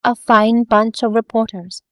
vox-cloned-data
Text-to-Speech
add clones